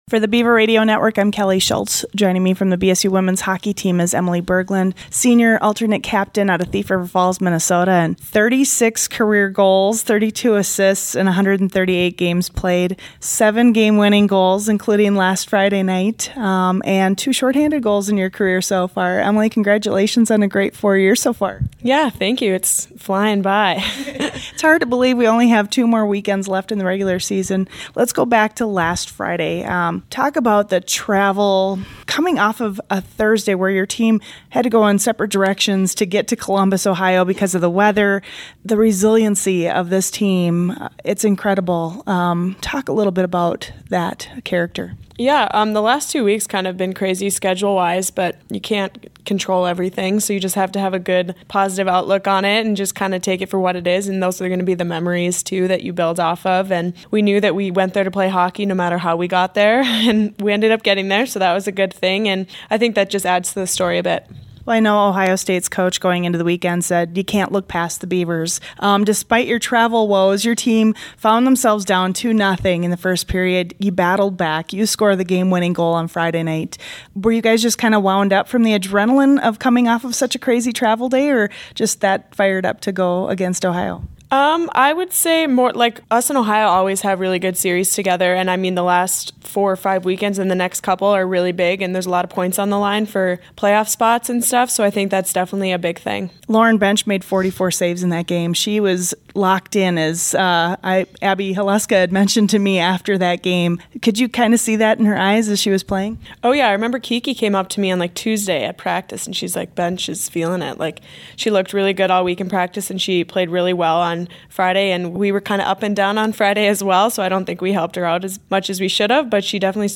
Player Pregame